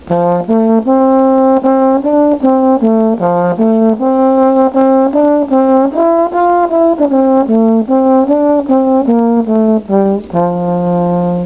The tenor sackbut is the most useful size and it is this instrument which has evolved into the modern tenor trombone.
Sackbuts
sacbut.wav